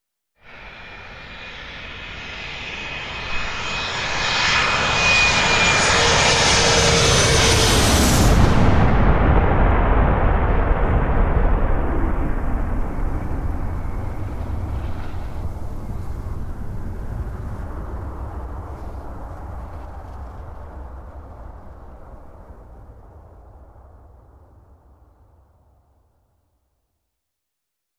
На этой странице собраны звуки авиакатастрофы — от гула падающего самолета до тревожных сигналов и хаотичных шумов.
Звук снижающегося самолета перед падением (пролетел прямо над головой)